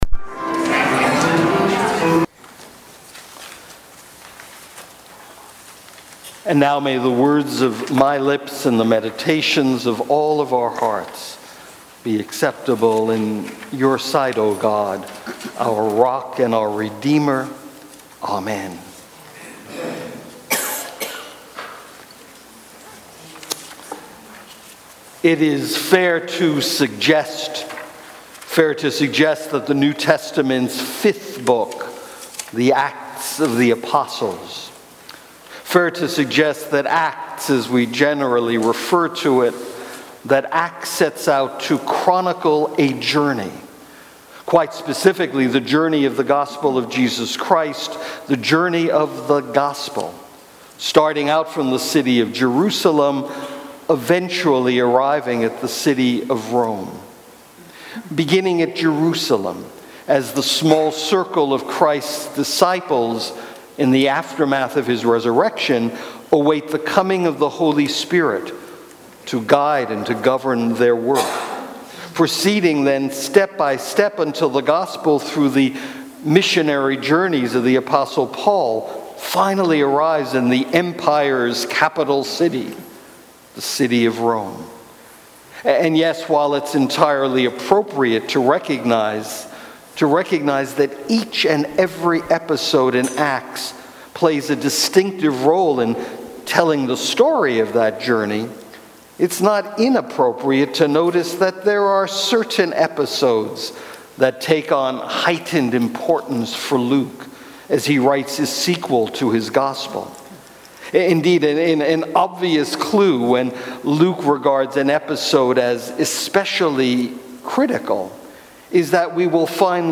Sermons | Trinity United Church